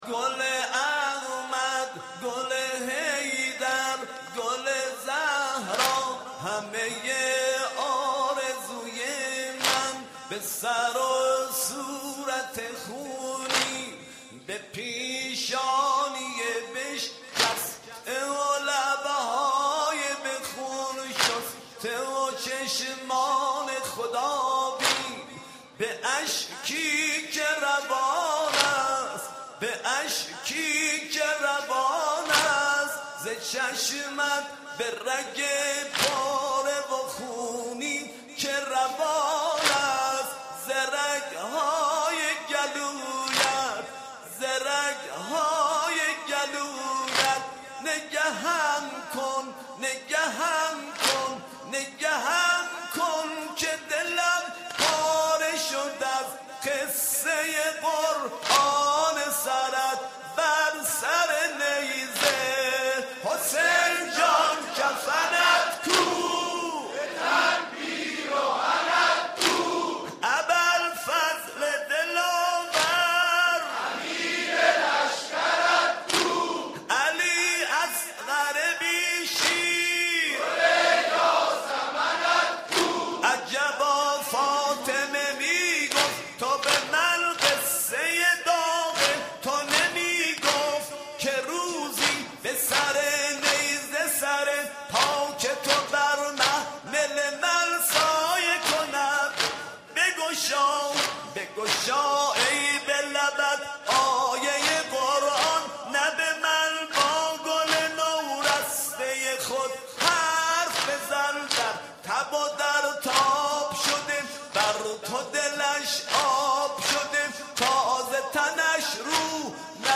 دانلود صوت: نوحه های هیئت جوادلائمه یزد در سال نود وسه